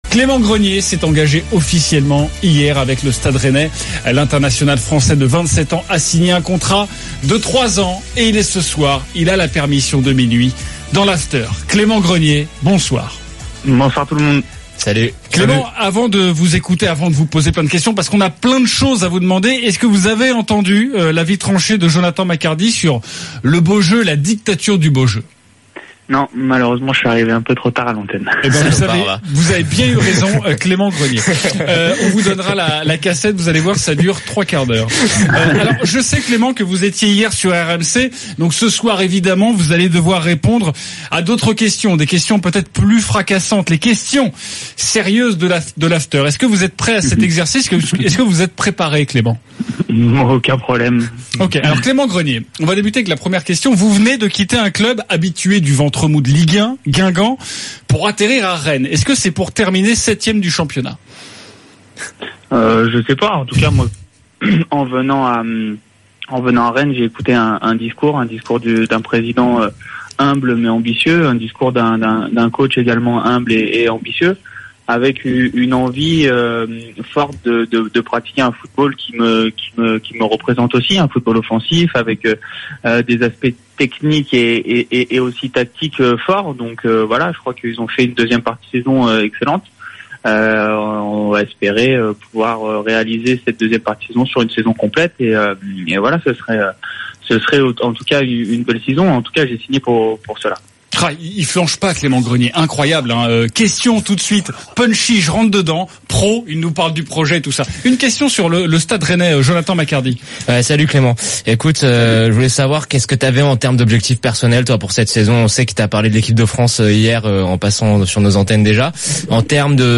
RMC : 25/07 - Le Top de l'Afterfoot : Retour sur l'interview de Clément Grenier, milieu de terrain de Rennes
Chaque jour, écoutez le Best-of de l'Afterfoot, sur RMC la radio du Sport.